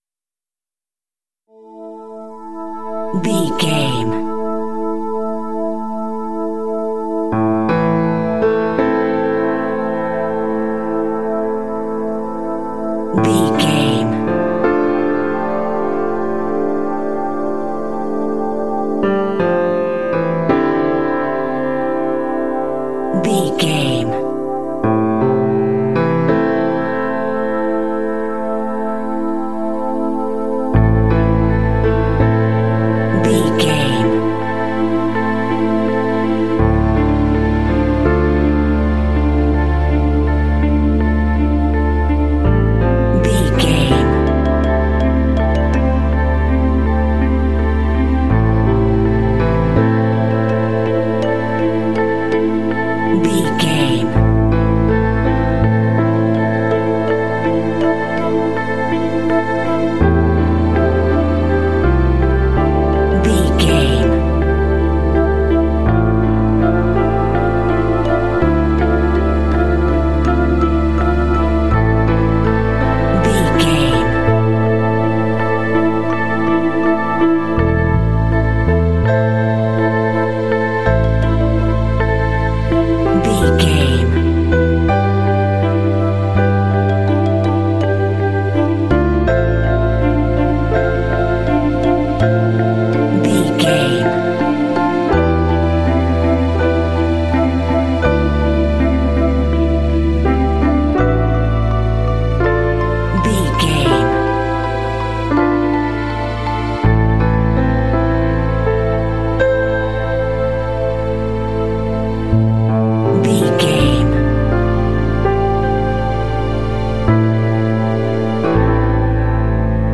Aeolian/Minor
Slow
dreamy
serene
synthesiser
piano
bass guitar
cinematic
film score